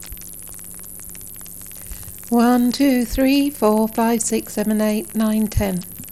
very loud noise recording with usb microphone
I’ve attached an example - it sounds like there is running water in the background.
I’m not sure where that noise is coming from - it’s not “typical” microphone noise.
It does sound to me like dirty USB power.